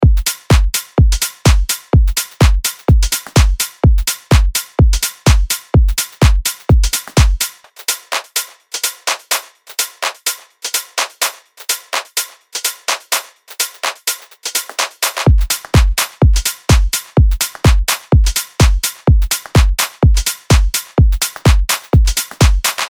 ダンスフロアに向けたパンチの効いた4つ打ちビートとグルーヴ
フロアを熱狂させる4つ打ちのキック、パンチのあるクラップ、シンコペーションの効いたハイハット、温かみを持つ質感のパーカッションで、あなたのトラックを躍動させましょう。
XO Expansion House プリセットデモ